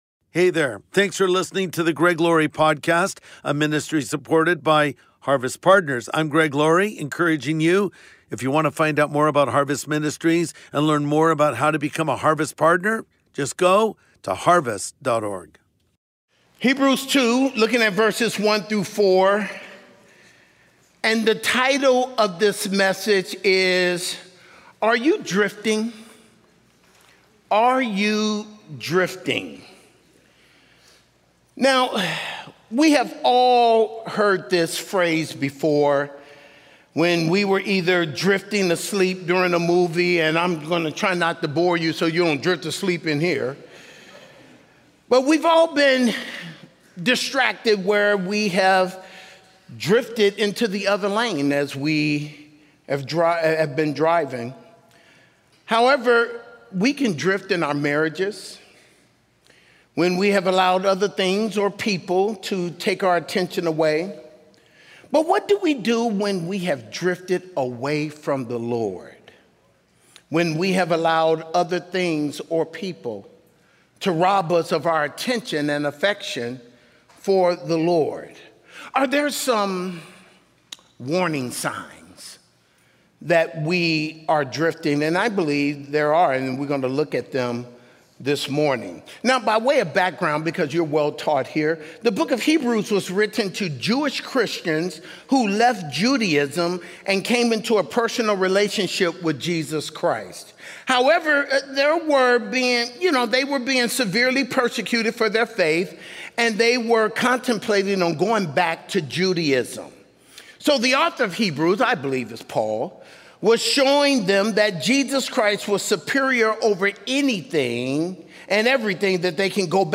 Are You Drifting? | Sunday Message